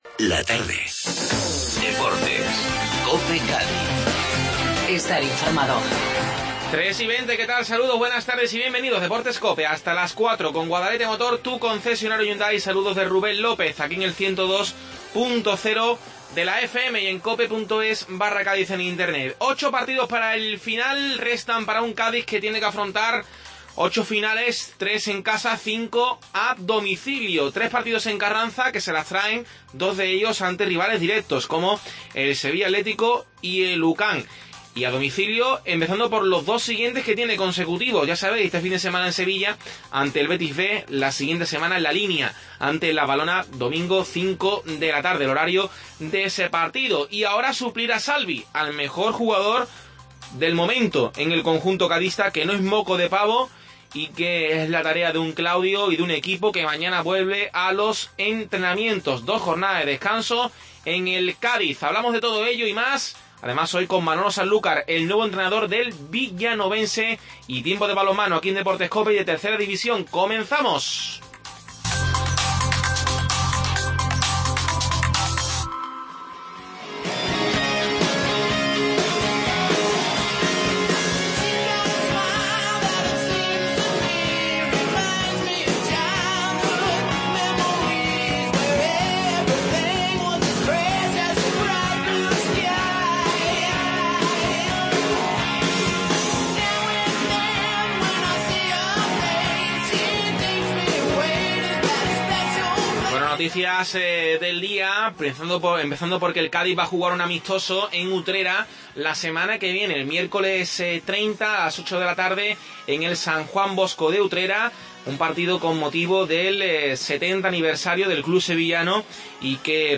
AUDIO: Actualidad del Cádiz. Tiempo de debate